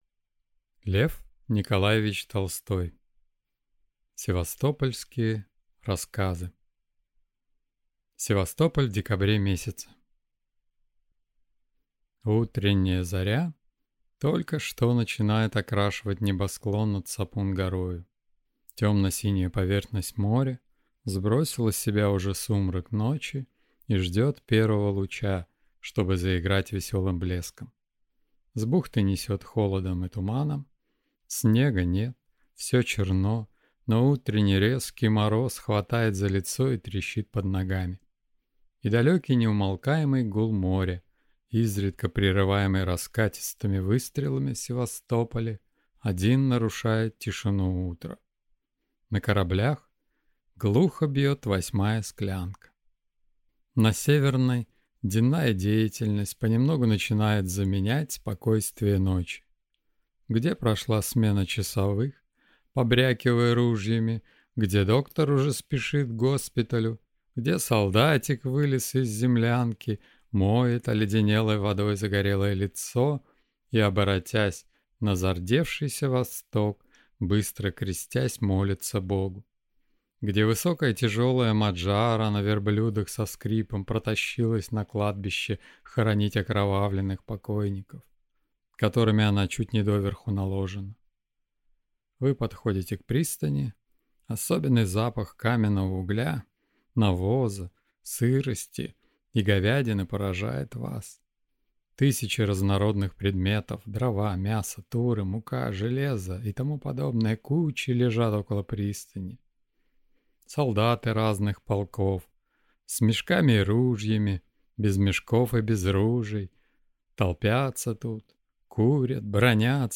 Аудиокнига Севастополь в декабре месяце | Библиотека аудиокниг